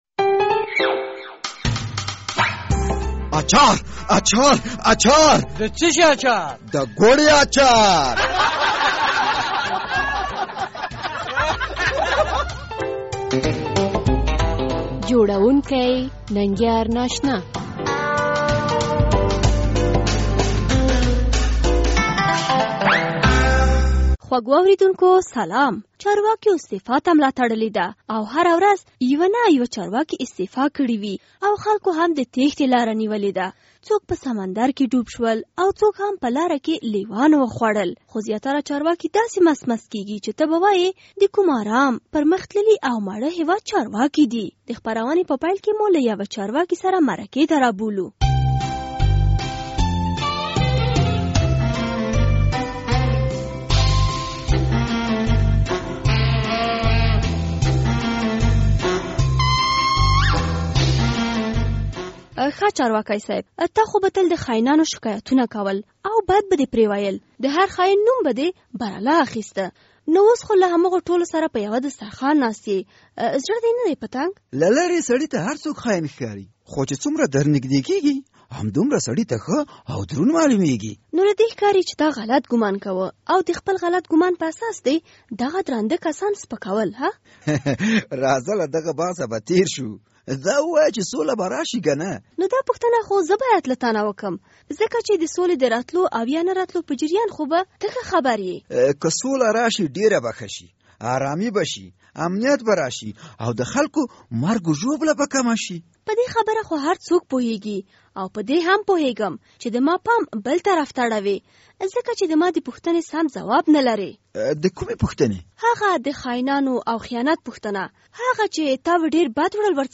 د ګوړې اچار په دې خپرونه کې به لومړی له یوه چارواکي سره مرکه واورئ چې نوموړي به پخوا په حکومت اوچارواکو پسې ...